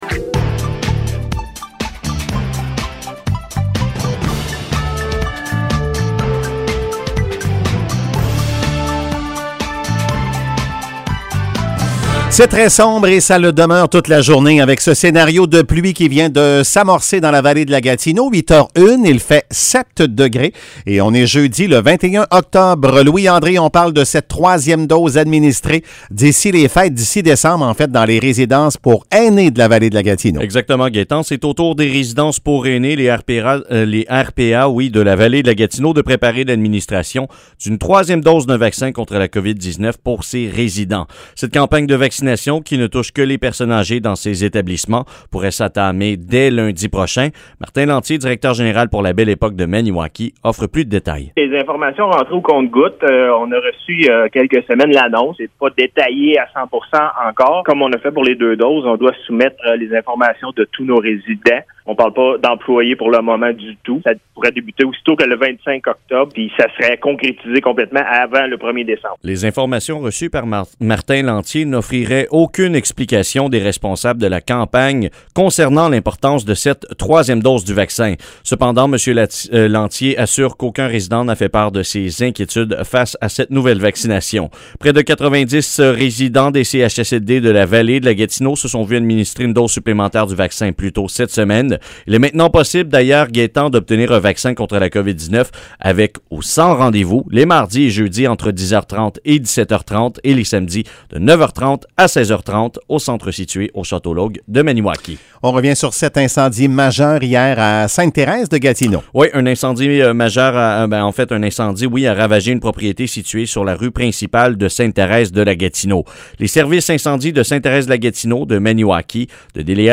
Nouvelles locales - 21 octobre 2021 - 8 h